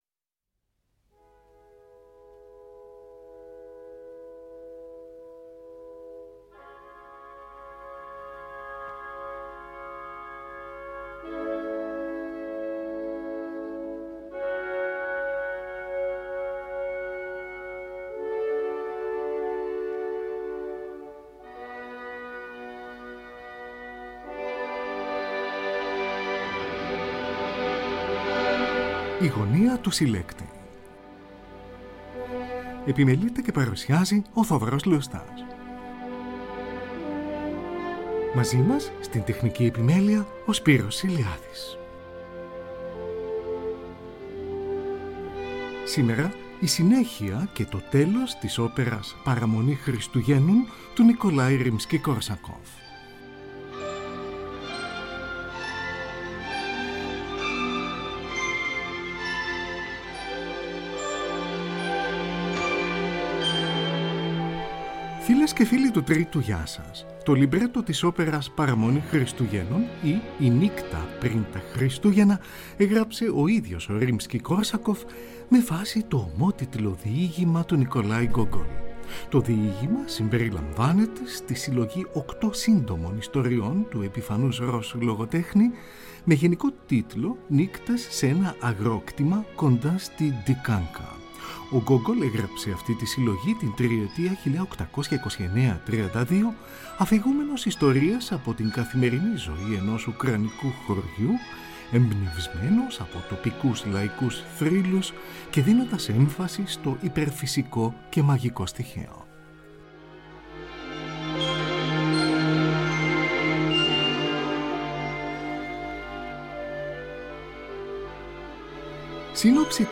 Πρώτη παγκόσμια ηχογράφηση .